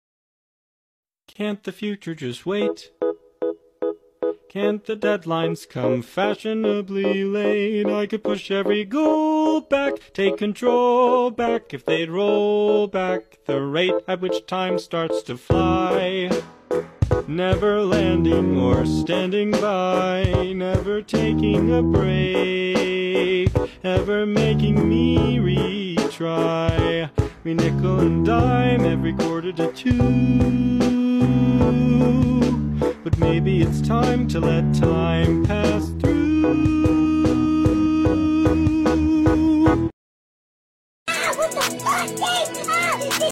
[Giggles..]•[Forsaken] [Tags : #007n7andc00lkiddforsaken#007n7forsaken#C00lkiddForsaken#angst#Shitpost sound effects free download